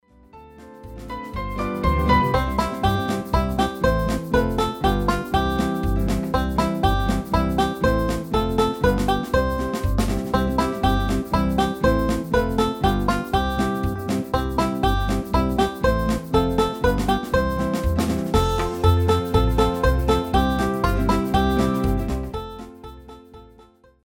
RÉPERTOIRE  ENFANTS
Le PLAY-BACK mp3 est la version
instrumentale complète, non chantée,